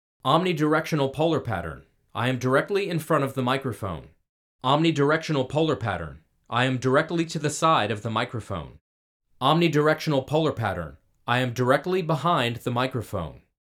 OMNIDIRECTIONAL
In this clip, take note of no dip in volume as I move around the microphone, but a bit more noise and room ambience.
vocals-omni-waveinformer.mp3